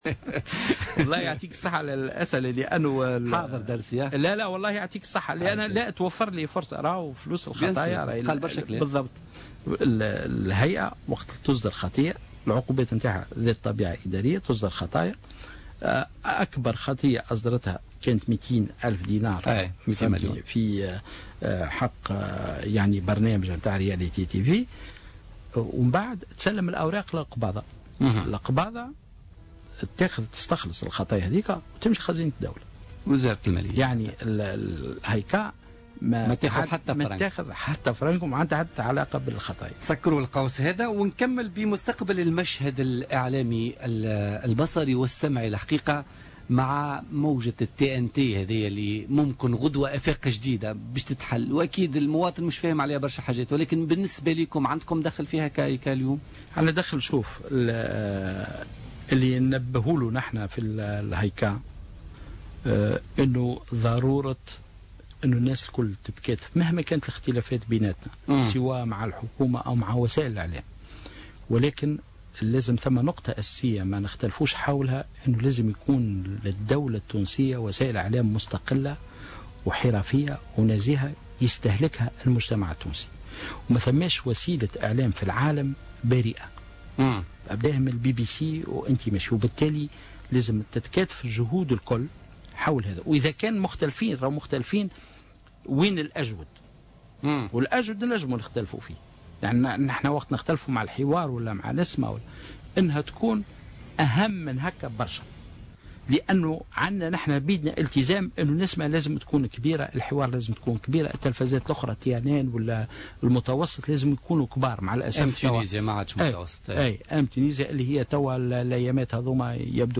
أكد عضو الهئية العليا المستقلة للاتصال السمعي البصري هشام السنوسي ضيف بوليتيكا اليوم الإثنين 25 أفريل 2016 أن الهايكا لم توافق على منح تقنية البث الجديدة التي ستعتمدها تونس لقنوات اذاعية وتلفزية عربية وأجنبية.